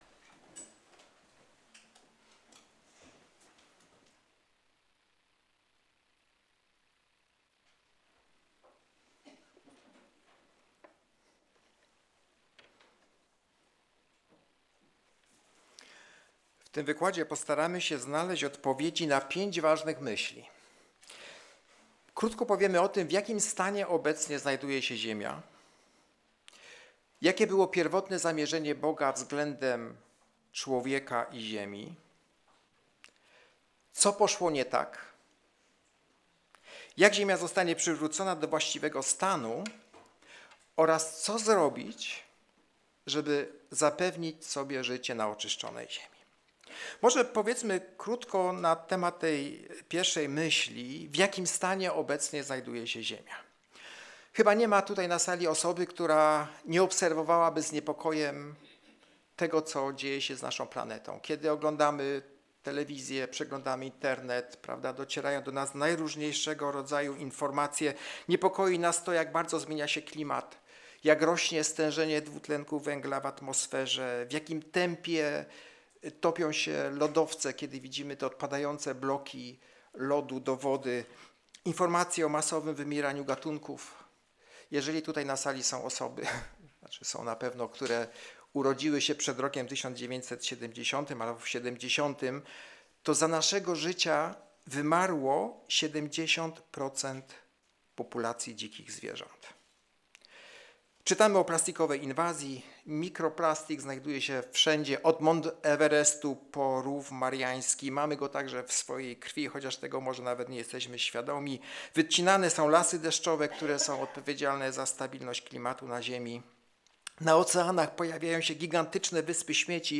Wykład Specjalny 2026.m4a